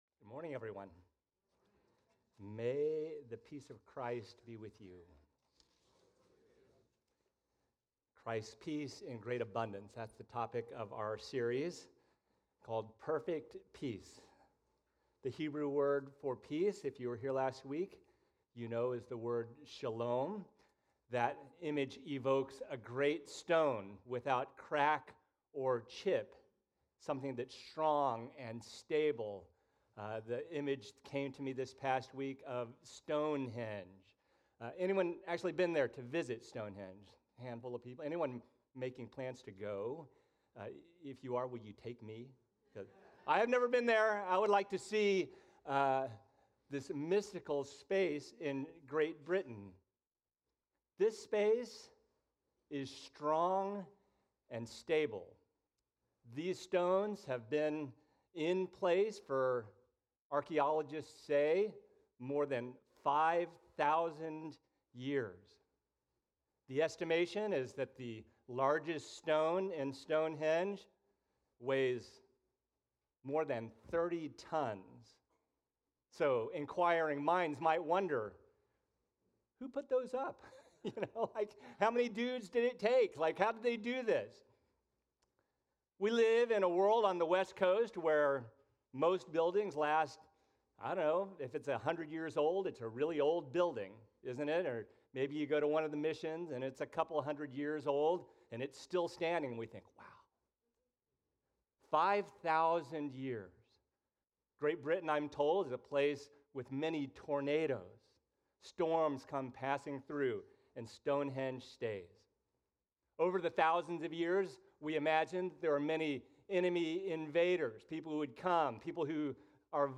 The River Church Community Sermons